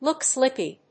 アクセントLòok slíppy!